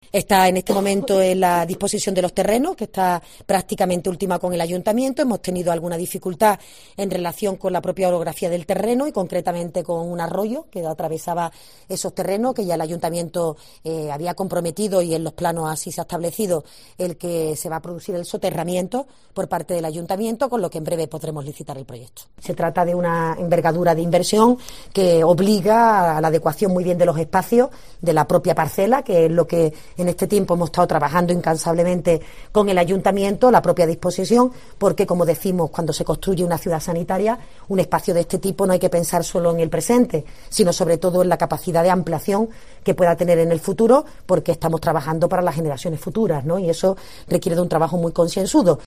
Chaves anunció el proyecto en un mitín de la campaña electoral de 2006 y ahora la Junta, gobernada por el PP, dice que por fín será una realidad en 2028
Chaves prometía en 2006 la Ciudad Sanitaria para Jaén